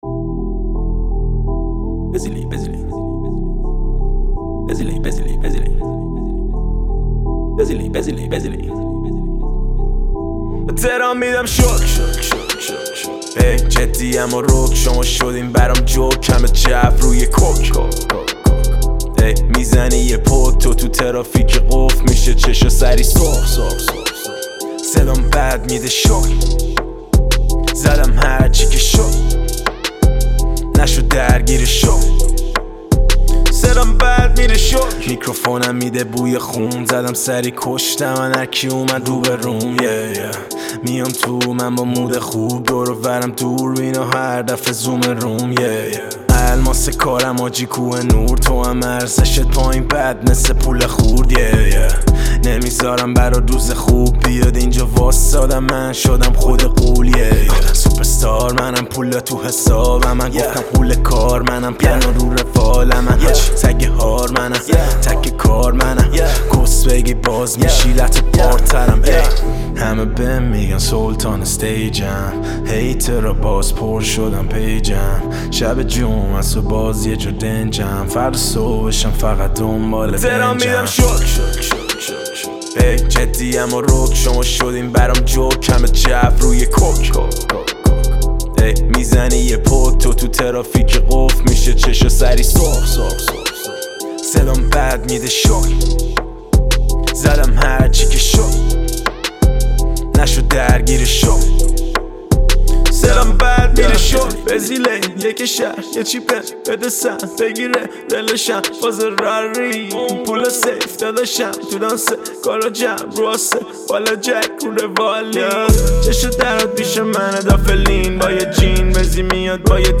تک آهنگ
رپ